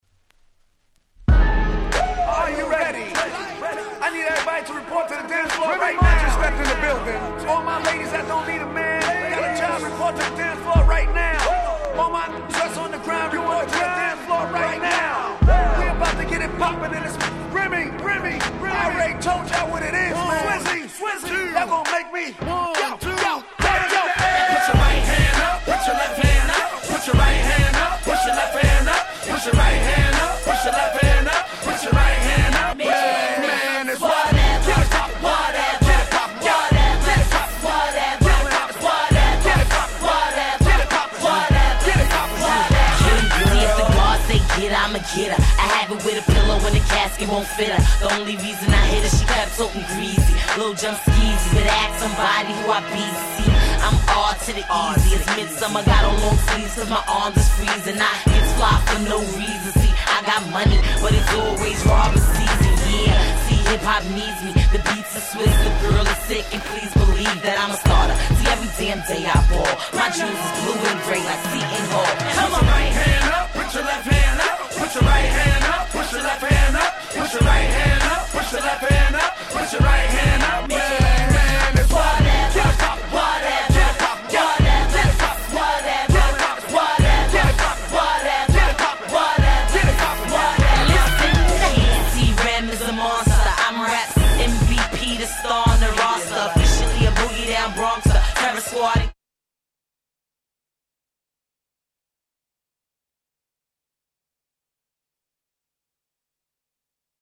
05' 大Hit Club Anthem !!
キャッチー系